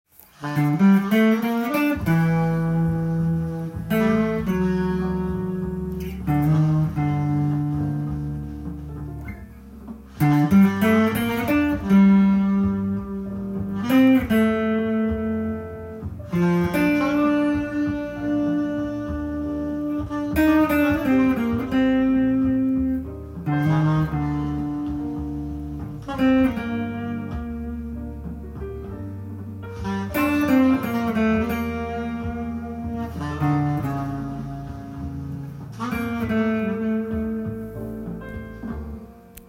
【The Shadow of Your Smile/ジャズスタンダード】
ギターメロディーtab譜
音源にあわせて譜面通り弾いてみました